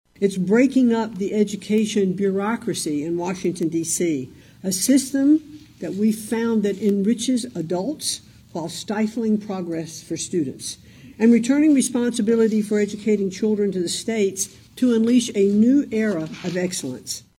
MCMAHON AND GOVERNOR REYNOLDS MADE THEIR COMMENTS AFTER TOURING AN ELEMENTARY SCHOOL IN DENISON AND VISITING WITH STUDENTS.